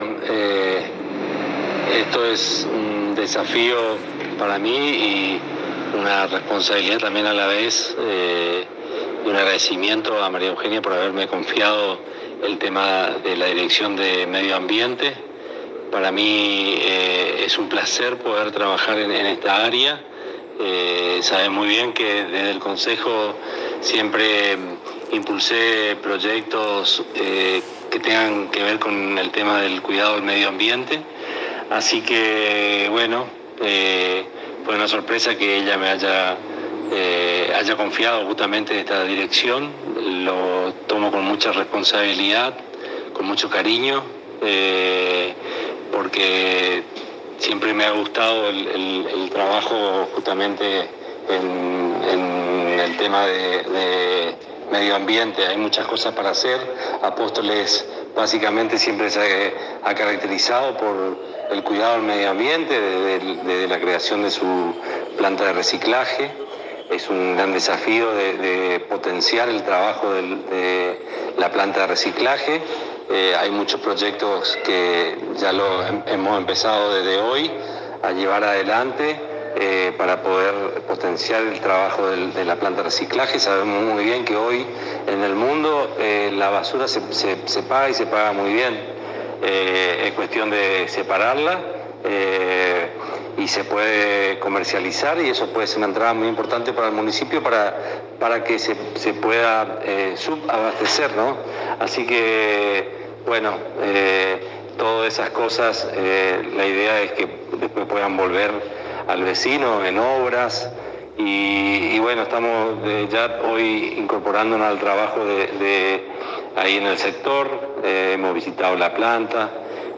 Audio: Alberto Daniel Poliszuk Dir. Medio Ambiente